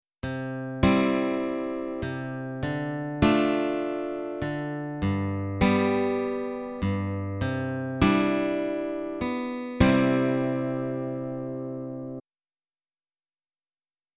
In this exercise, we will play multiple strings at the same time.
Chordal Finger Picking - Exercise 2
e2_chordalfingerpicking.mp3